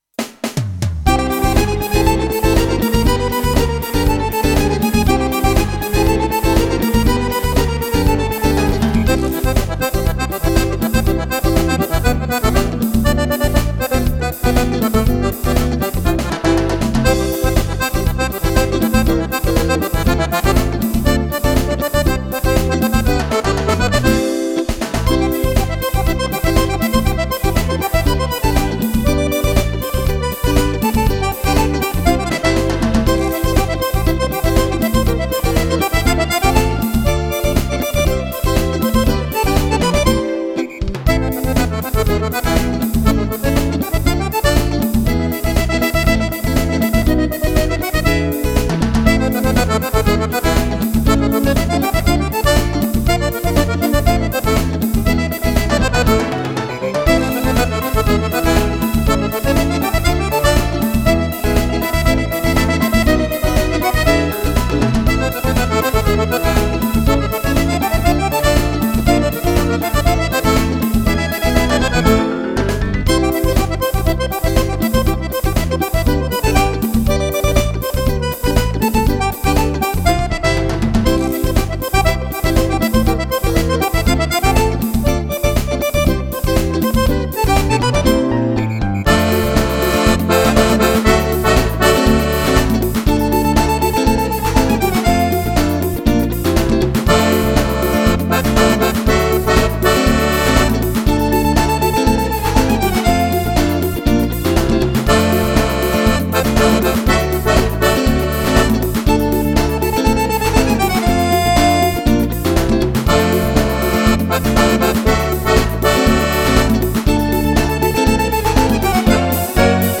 ballabili per sax e orchestra stile Romagnolo.